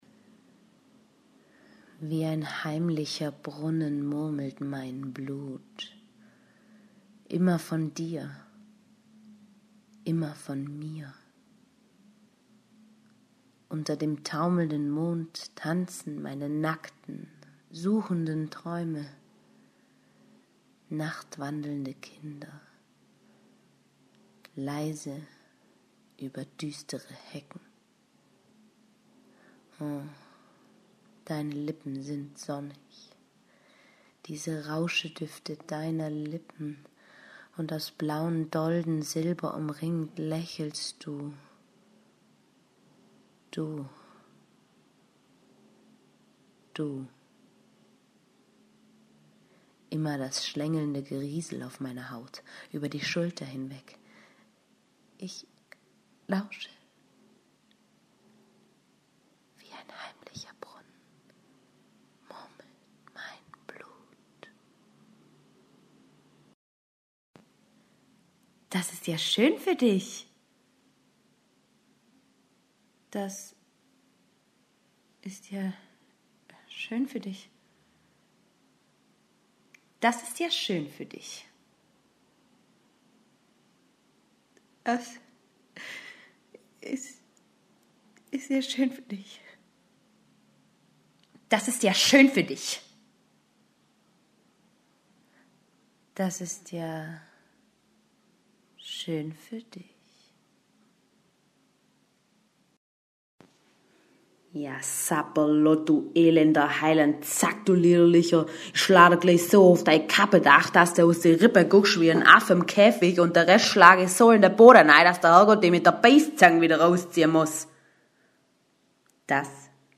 Sprachproben
Hier einige Sprachproben von mir: